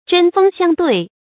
注音：ㄓㄣ ㄈㄥ ㄒㄧㄤ ㄉㄨㄟˋ
針鋒相對的讀法